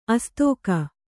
♪ astōka